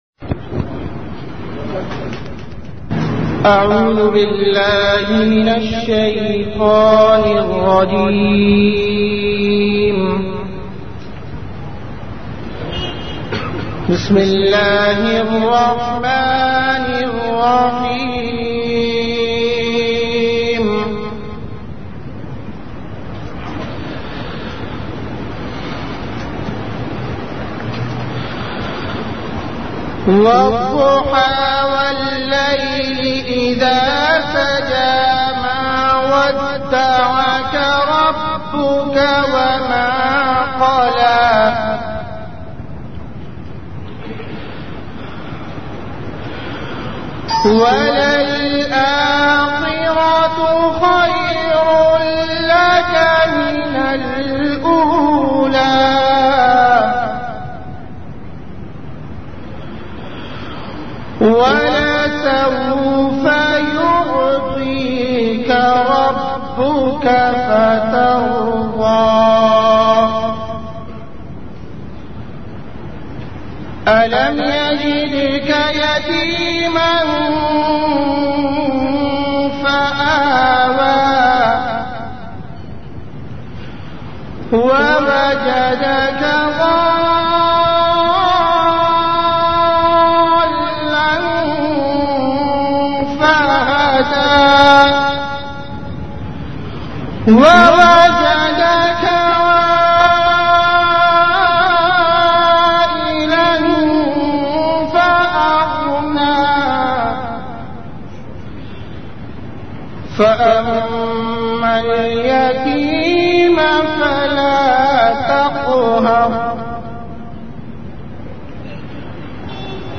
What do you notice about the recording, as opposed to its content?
Venue Home Event / Time After Isha Prayer